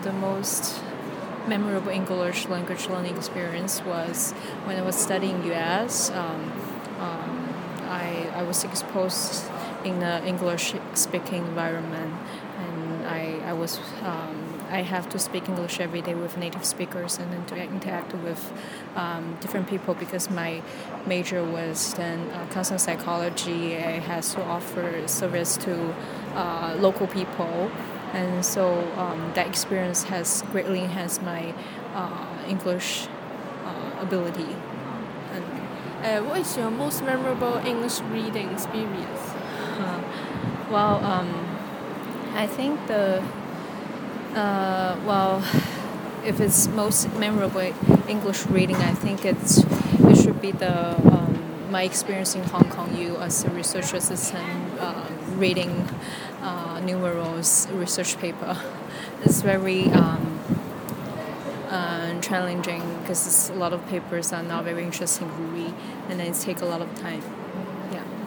Subcategory: Career, Non-fiction, Reading, Speech, Travel